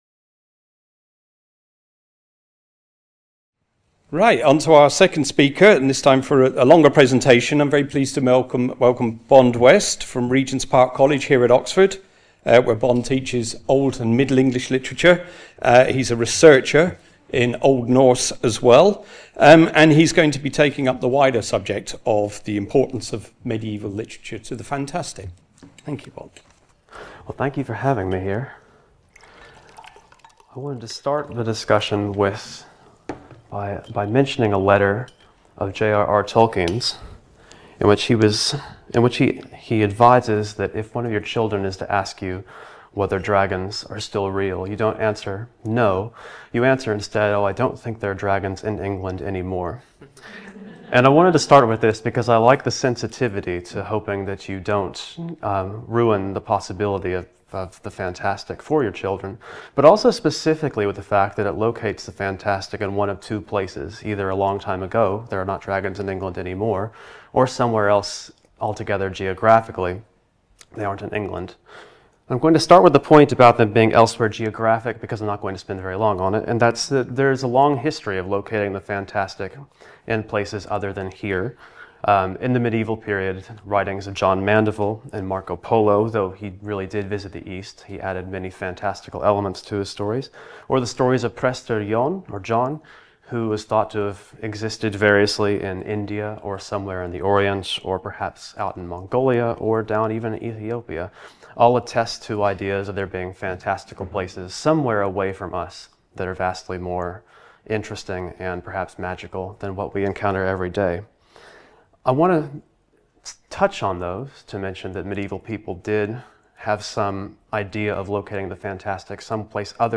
Part of the Bloomsbury-Oxford Summer School (23rd-25th September 2025) held at Exeter College.